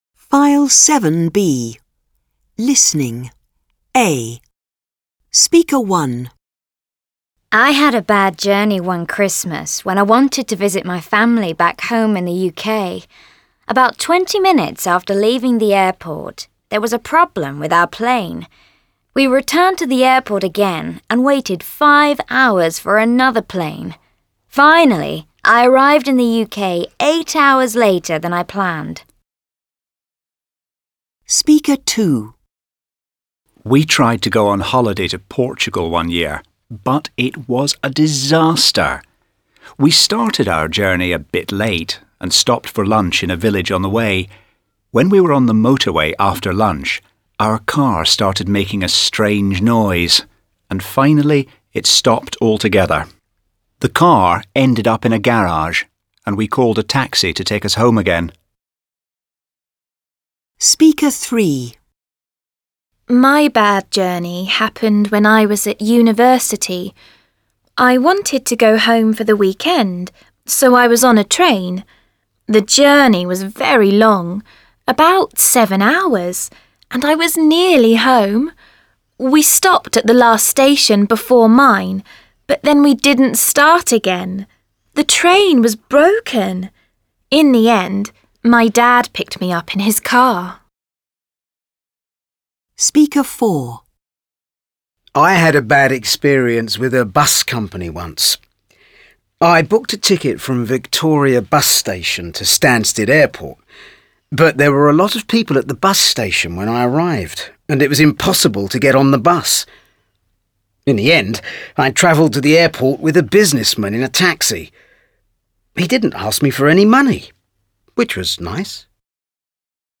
Mf 46/5 Hallgasd meg a 4 embert akik az utazásukról mesélnek!